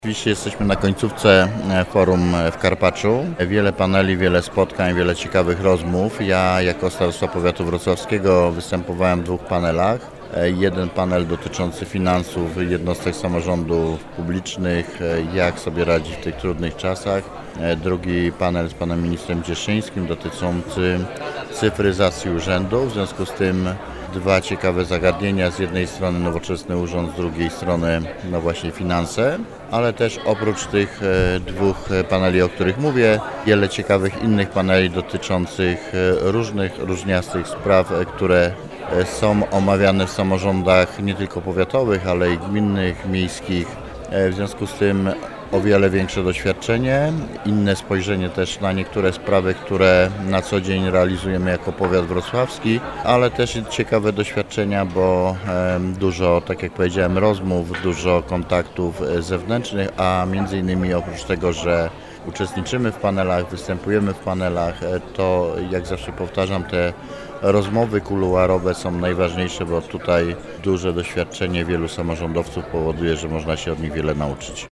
Mówi Roman Potocki – Starosta Powiatu Wrocławskiego.